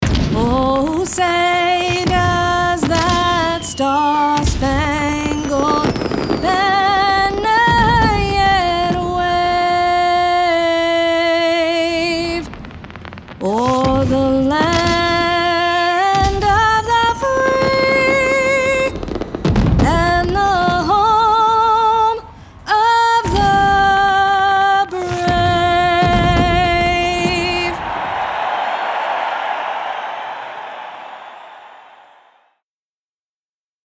anti-announcer, classy, confident, friendly, genuine, motivational, singers, Straight Forward, warm